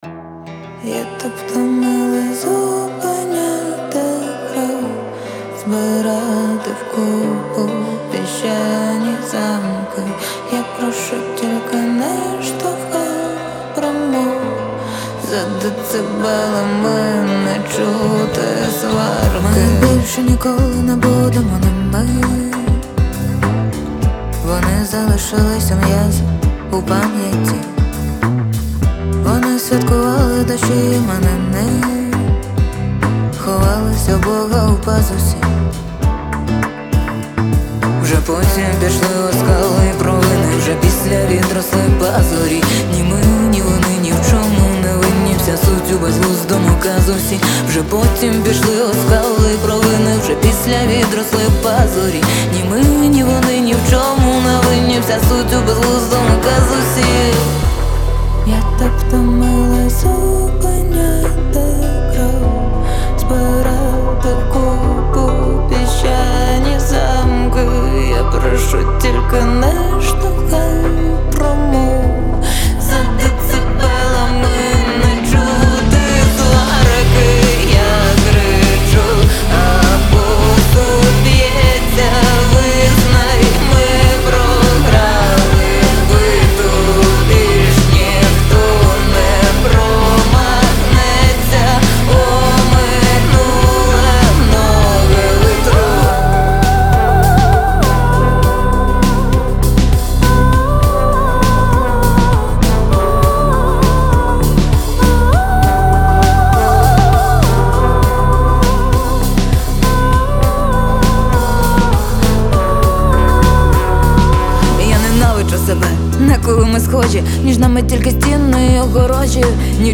• Жанр: Pop, Indie